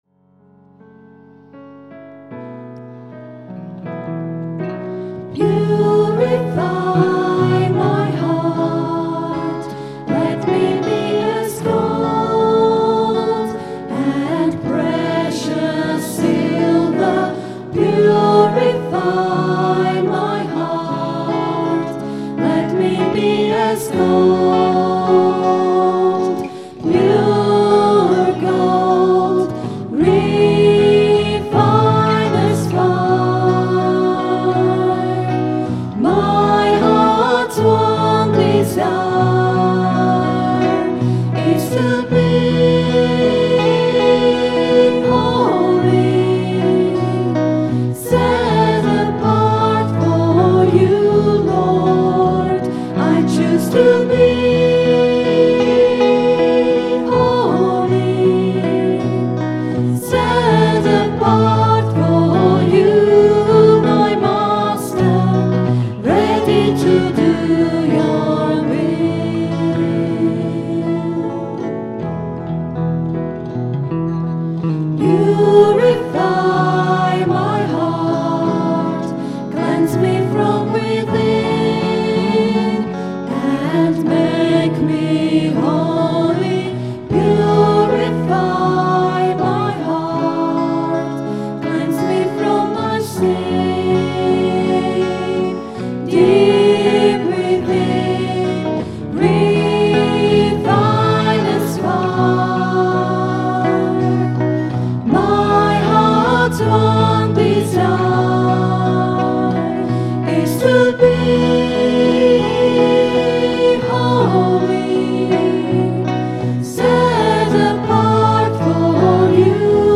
A well known and popular hymn, this song was recorded at 10am Mass on Sunday 20th July 2008. Recorded on the Zoom H4 digital stereo recorder through a Behringer SL2442FX mixer.